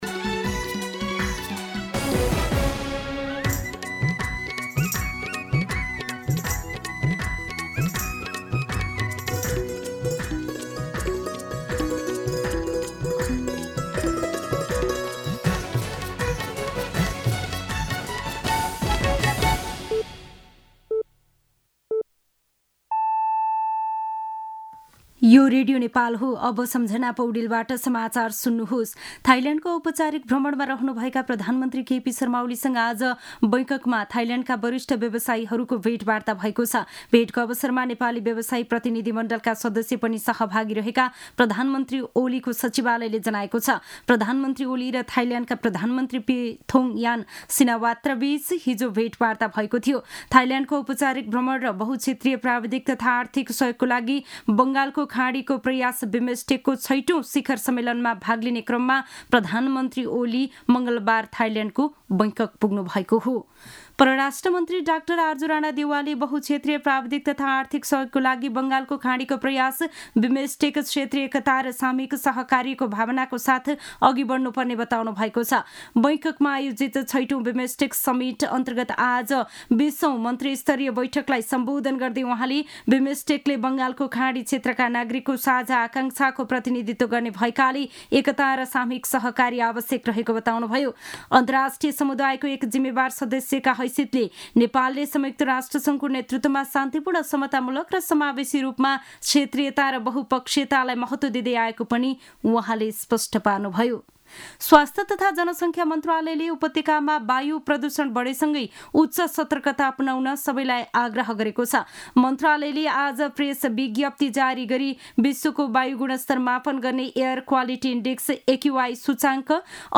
दिउँसो १ बजेको नेपाली समाचार : २१ चैत , २०८१
1-pm-Nepali-News.mp3